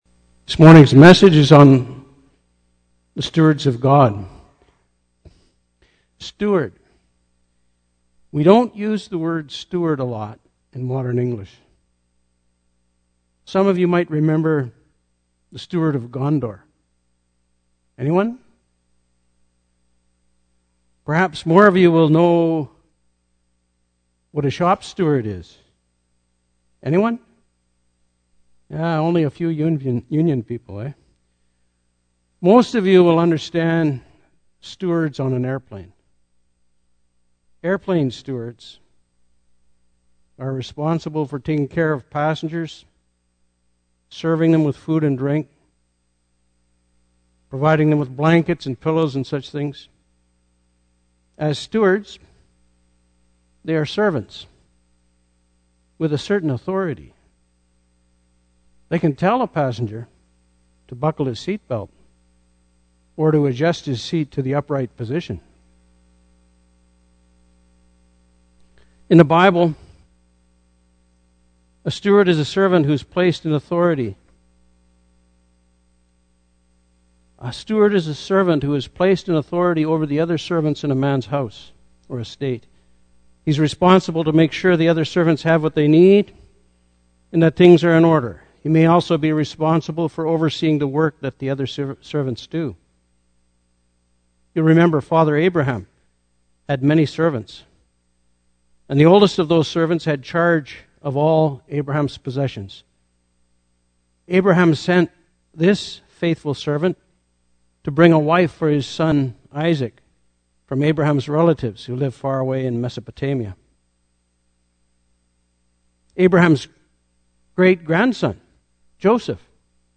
The Bible identifies Elders as leaders in the church who are to guide their fellow believers to Christ. In this sermon, we take a closer look at the qualifications required for this important church leadership role.